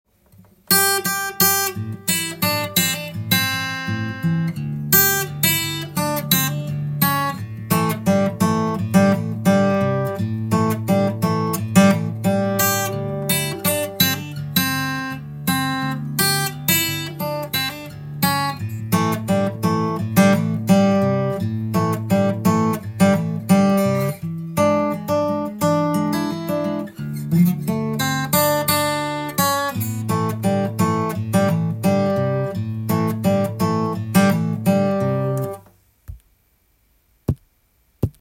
ソロでメロディーやコード、ベースなどをすべて弾いてしまうので
一人ソロギターtab譜
譜面通り弾いてみました
チューニングは６弦のみDに音程を下げて弾くtab譜です。
基本的に右手親指で低音弦を弾きますので